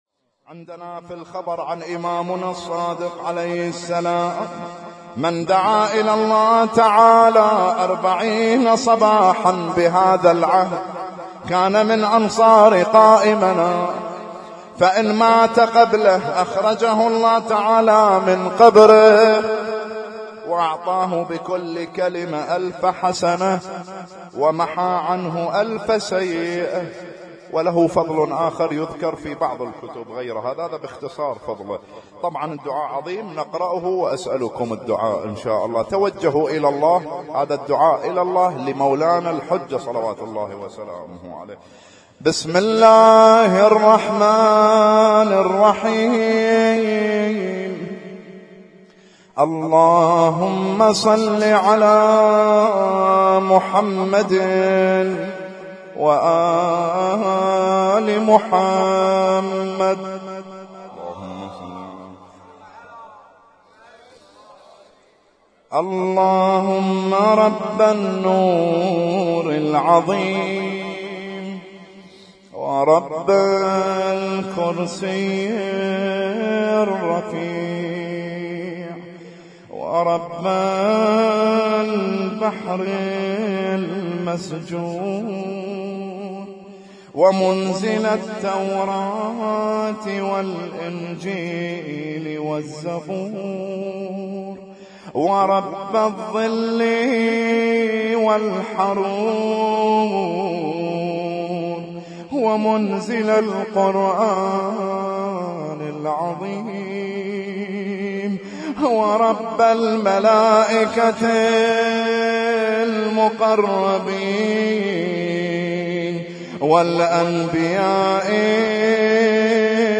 Husainyt Alnoor Rumaithiya Kuwait
اسم التصنيف: المـكتبة الصــوتيه >> الادعية >> الادعية المتنوعة